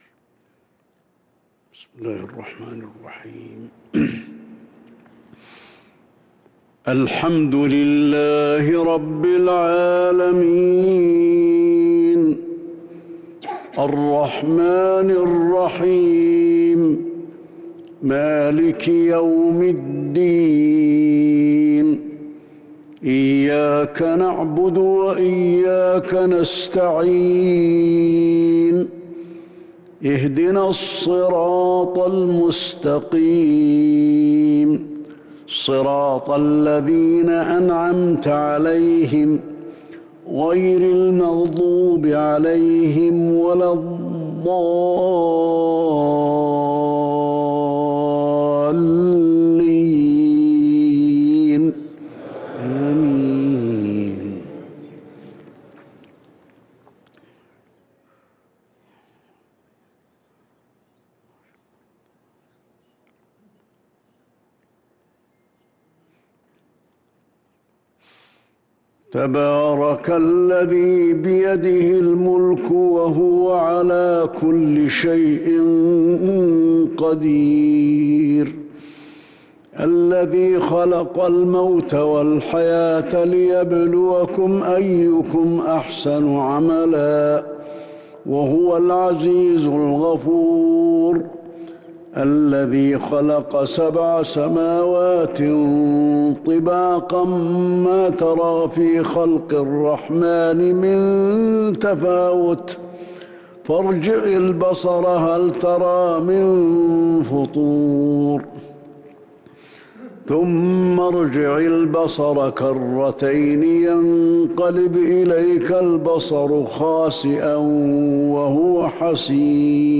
عشاء الإثنين 6-7-1443هـ فواتح سورة الملك |  Isha prayer from Surah al-Mulk 7-2-2022 > 1443 🕌 > الفروض - تلاوات الحرمين